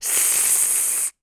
snake_hiss_02.wav